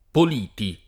pol&ti] cogn. — dal letterato Giovan Battista Politi o Puliti il tit. del dialogo Il Polito (1525) di C. Tolomei — qualche famiglia, però,